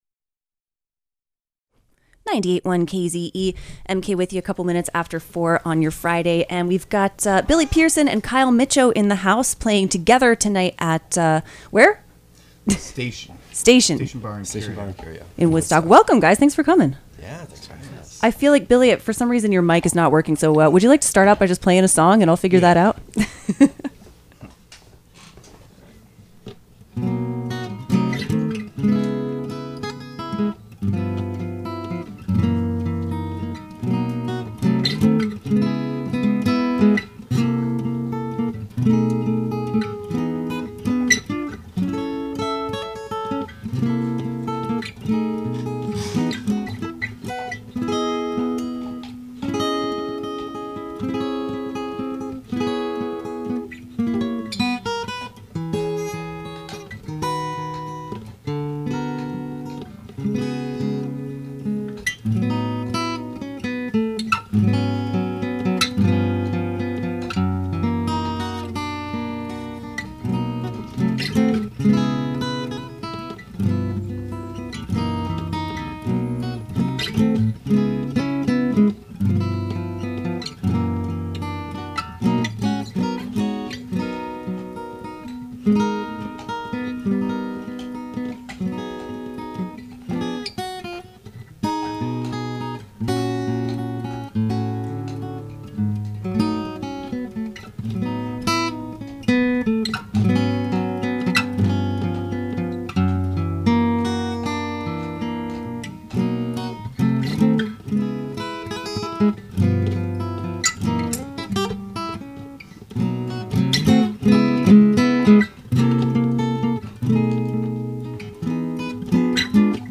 WKZE interview w